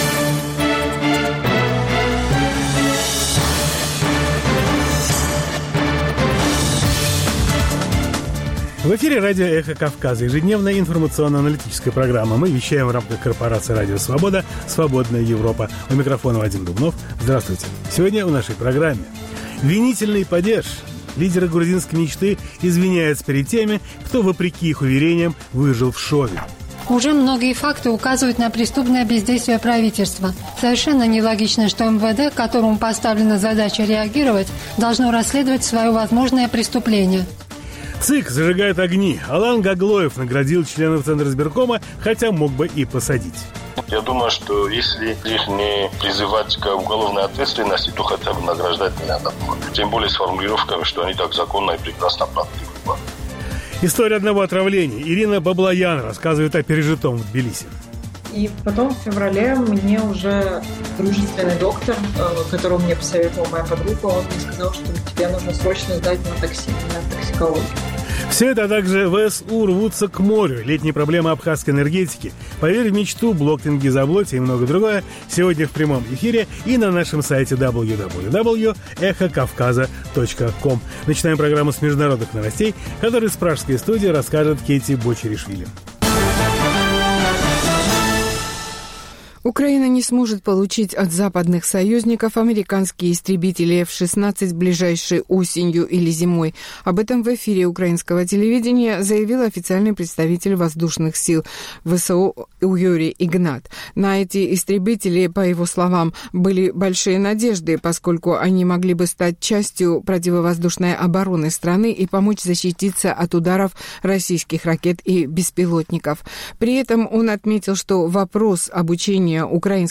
Новости, репортажи с мест, интервью с политиками и экспертами, круглые столы, социальные темы, международная жизнь, обзоры прессы, история и культура.